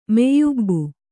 ♪ meyyubbu